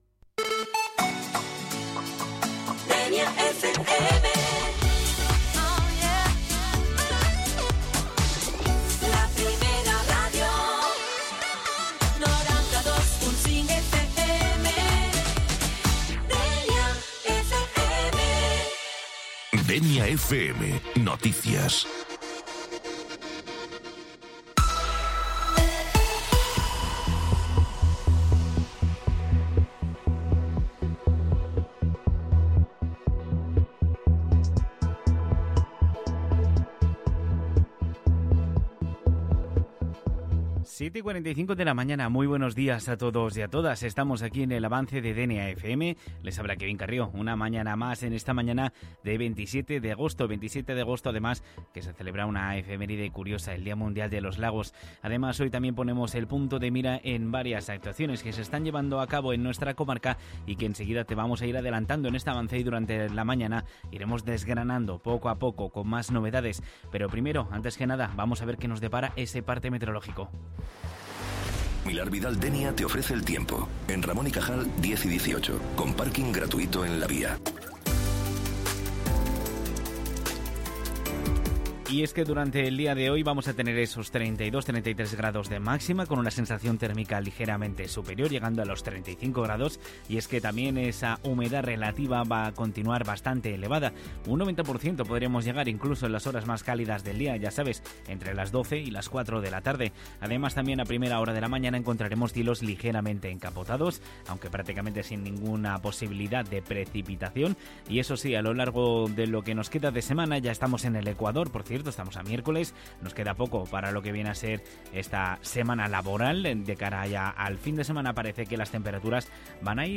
Sintoniza las noticias del día con Dénia FM